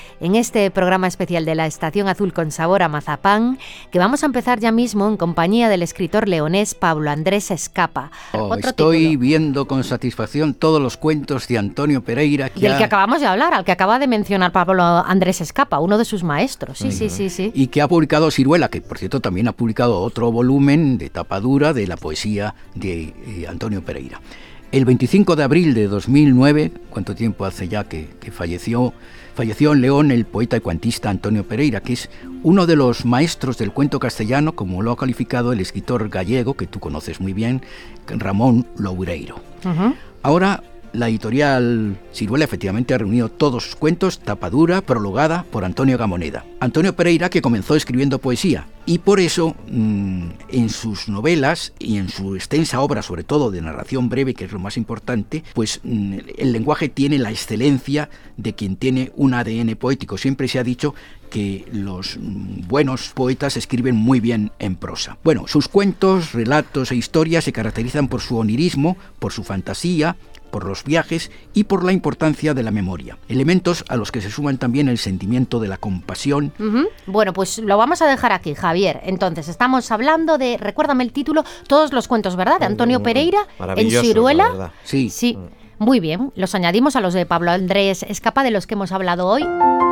Programa «La estación azul» emitido por RTVE Radio